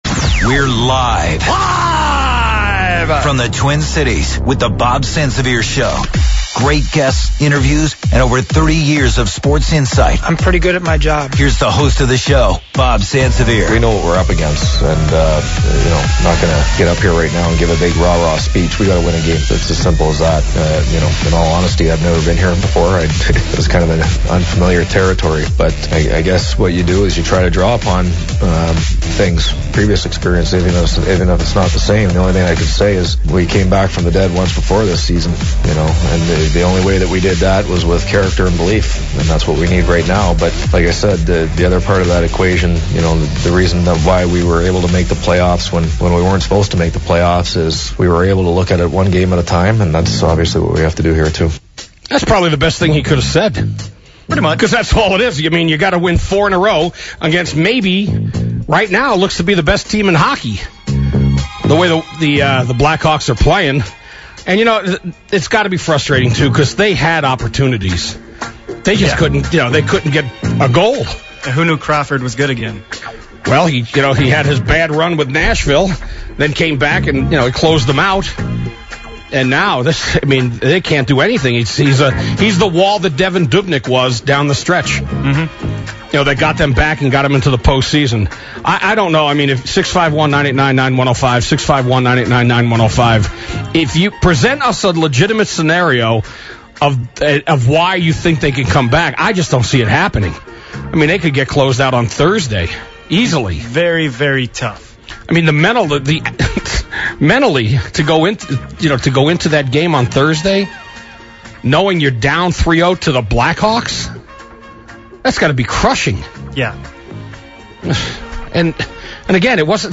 hops in studio for a segment.